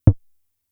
Index of /90_sSampleCDs/300 Drum Machines/Fricke Schlagzwerg/Kicks
Kick (2).WAV